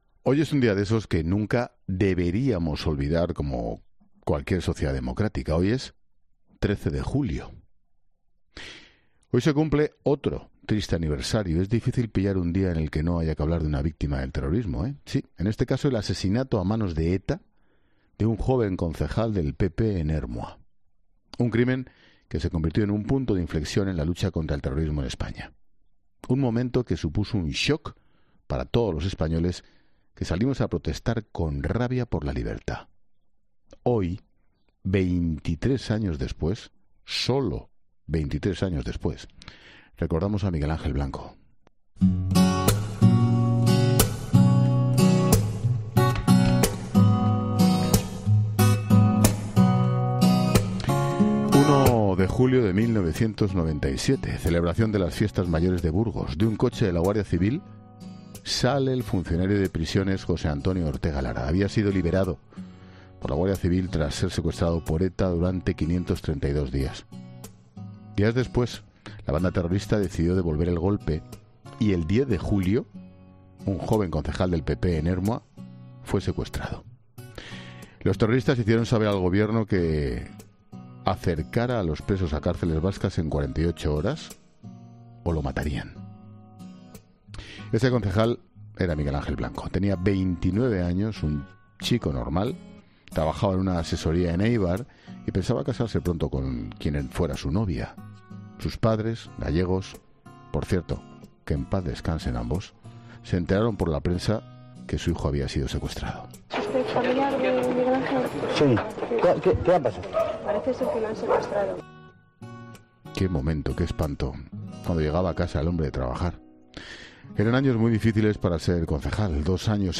Expósito ha aprovechado el relato de lo sucedido para leer un correo que recibieron él y su equipo, cuando estaba en ‘La Tarde’, al hilo del suceso.